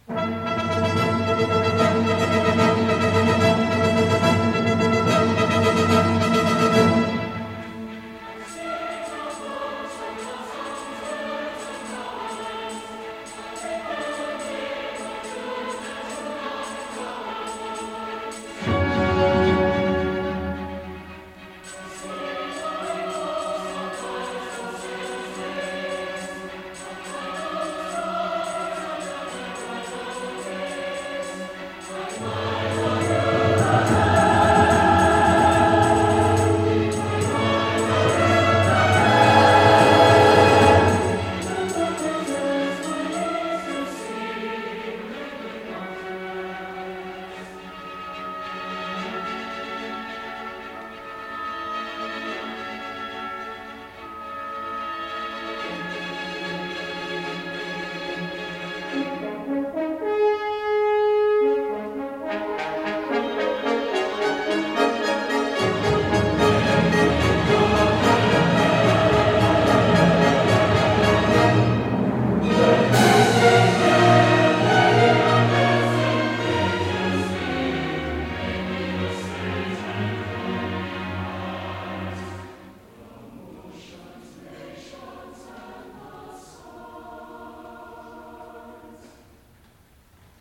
for Soprano, Baritone, Chorus and Orchestra